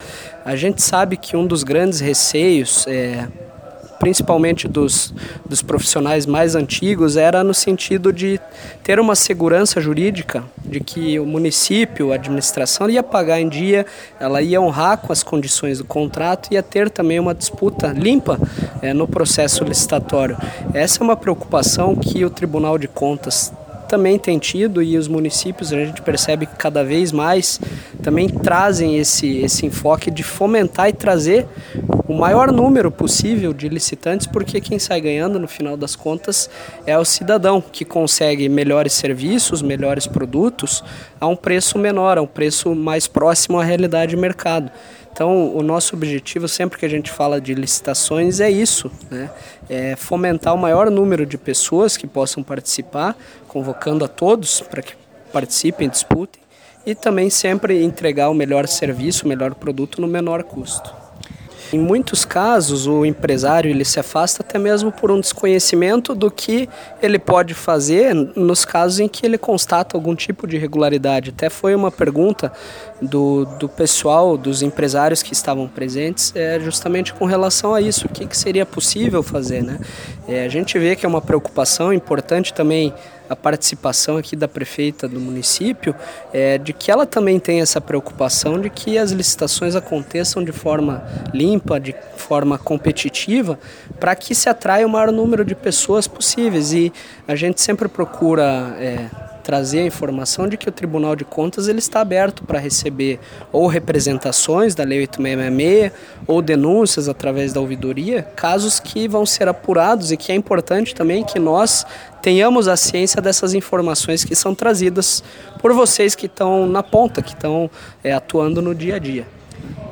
O Seminário Regional de Compras Públicas, realizado no município de Andirá, na última quarta-feira (7), reuniu representantes dos setores administrativos (em especial a área de compras) de dezessete prefeituras do Norte Pioneiro.